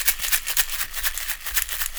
African shaker 120bpm.wav